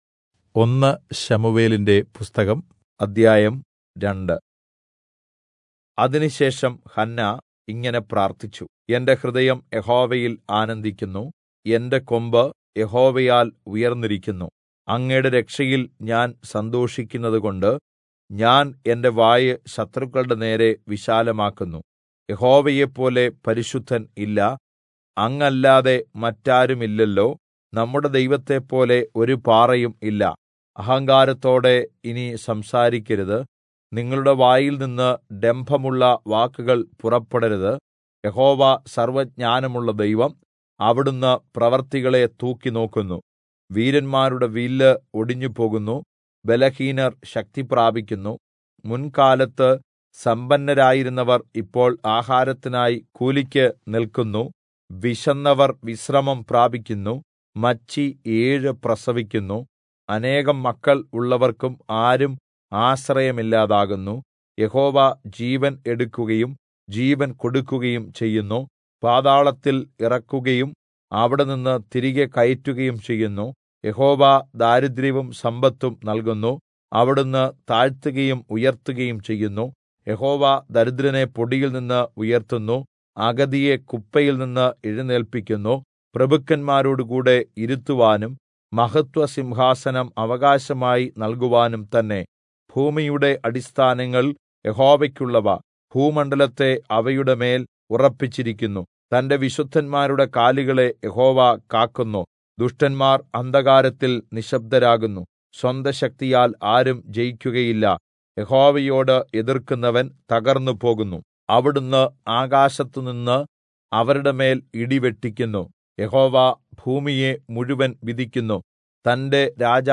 Malayalam Audio Bible - 1-Samuel 25 in Irvml bible version